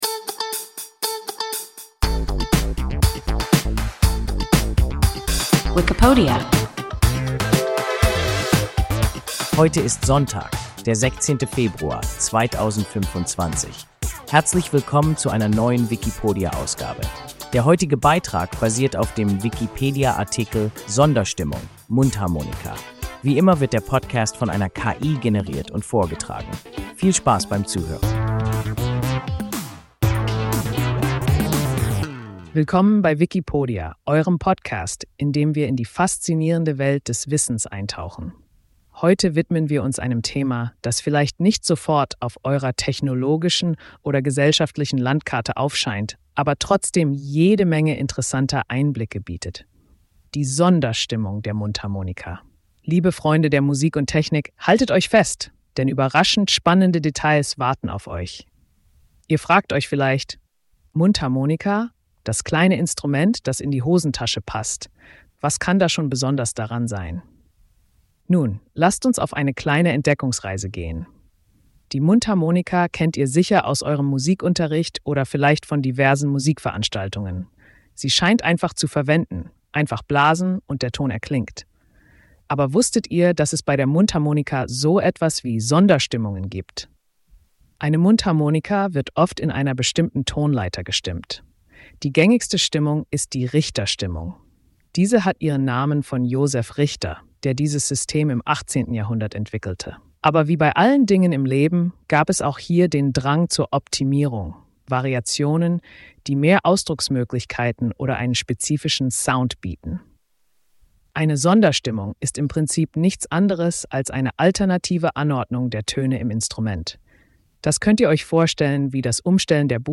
Sonderstimmung (Mundharmonika) – WIKIPODIA – ein KI Podcast